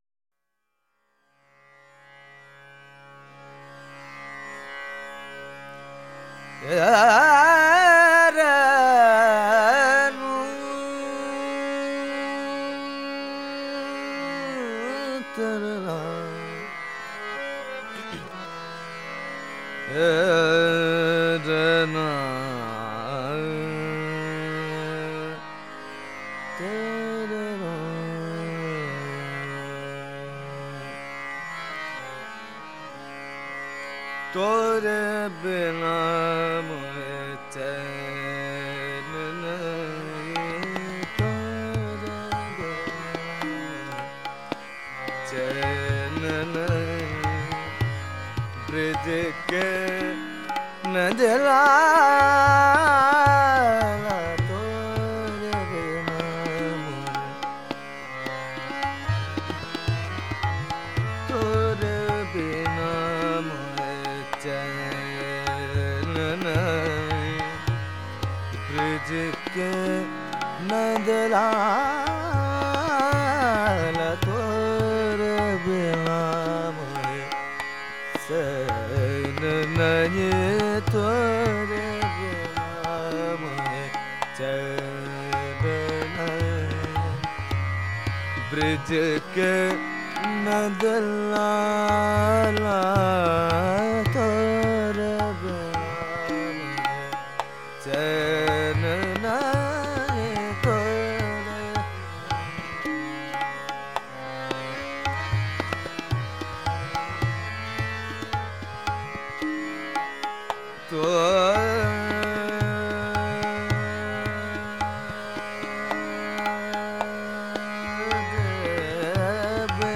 Rashid Khan, a preeminent Hindustani classical vocalist of the Rampur-Sahaswan gharana, passed away on Jan 9th.